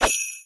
sh_bell_c_1.wav